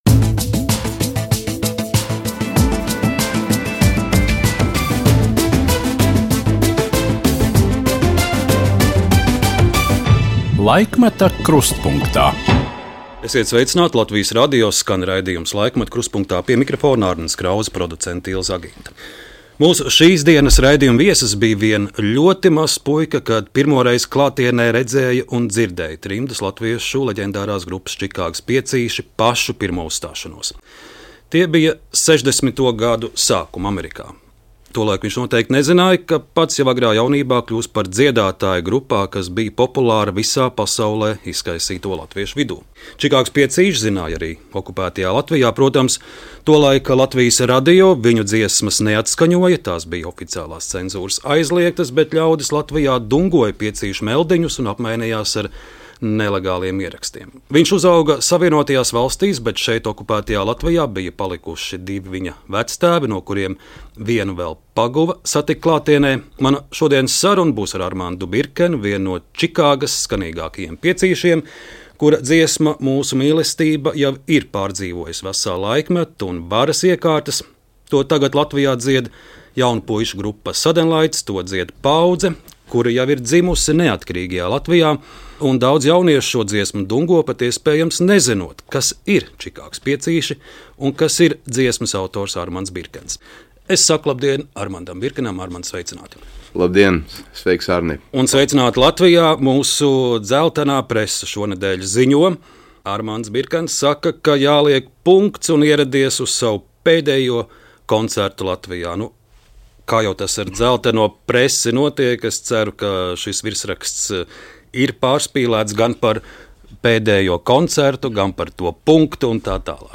Par vectēvu olimpieti, tikšanos ar Jāni Zāberu, par Selīnas Dionas kāzām un kāpēc Amerika sajukusi prātā – Armands Birkens sarunā Laikmeta krustpunktā.